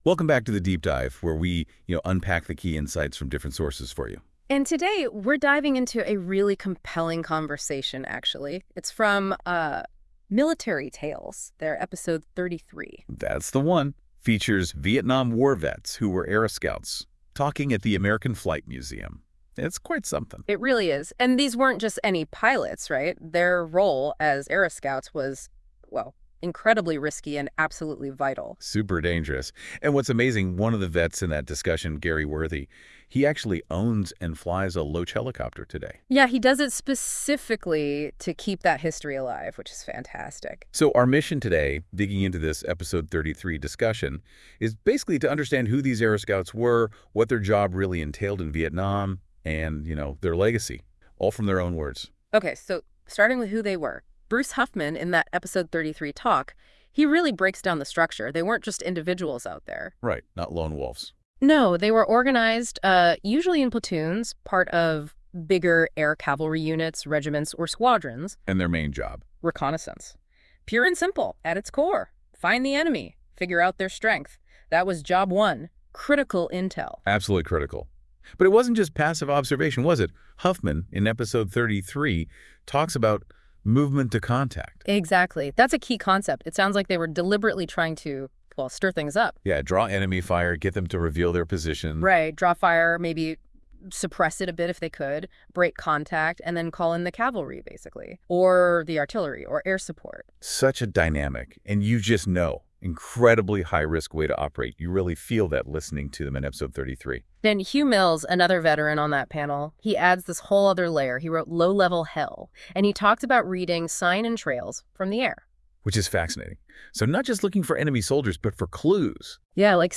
” featuring a rare discussion with three Vietnam War Aero Scouts